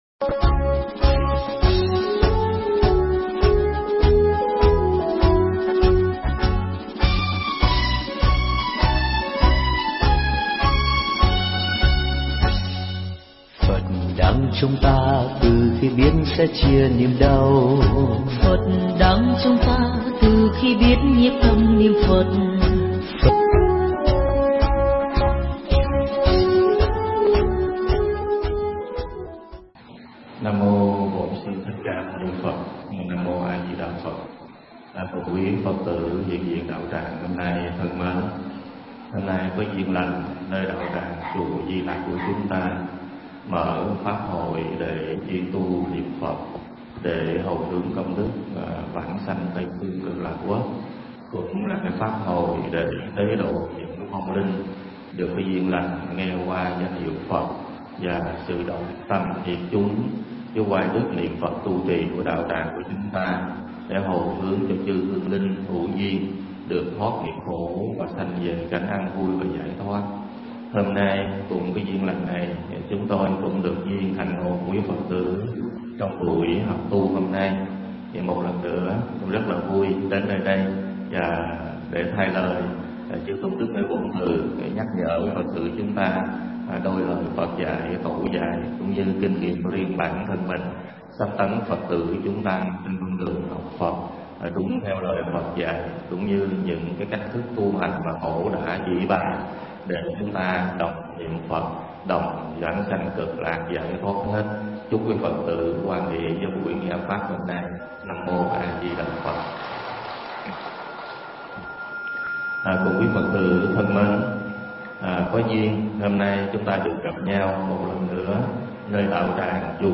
Pháp Thoại
giảng tại Chùa Di Lặc